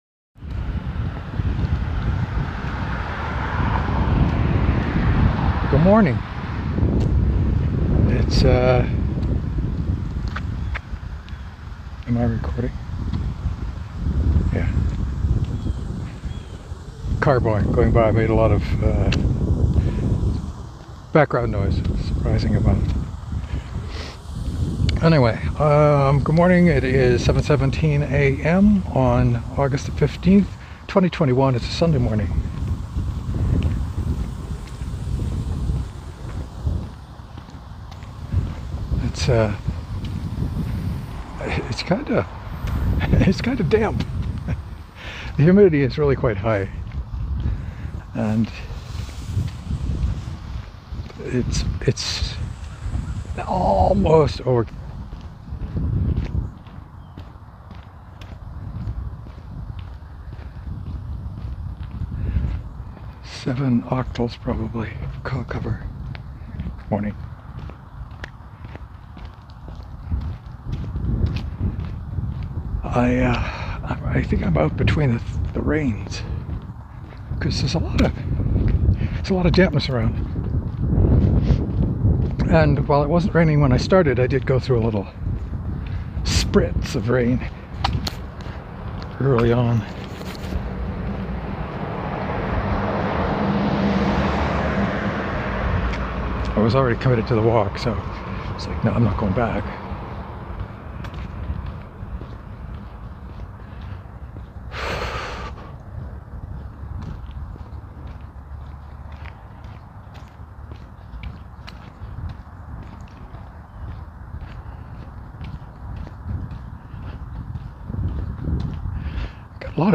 Something weird happened at the end when the recording paused just after minute 17.